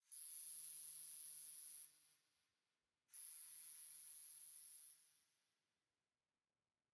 firefly_bush7.ogg